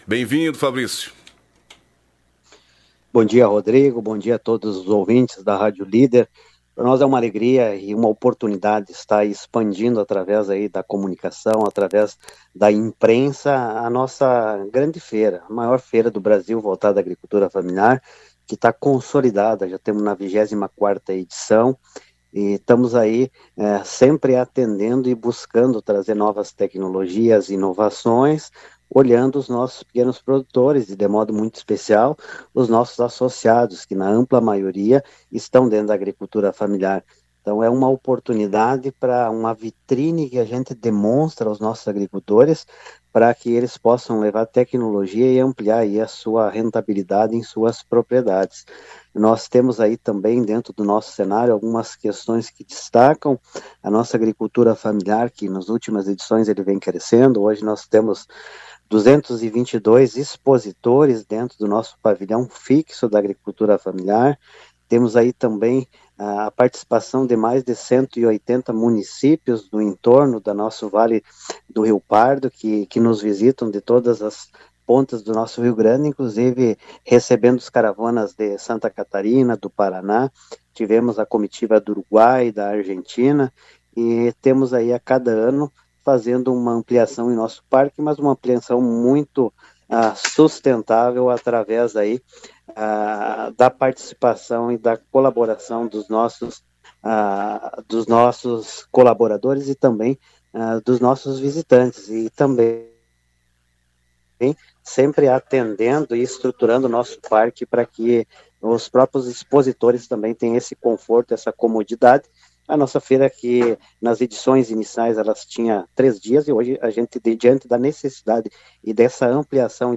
Em entrevista ao programa Giro da Notícia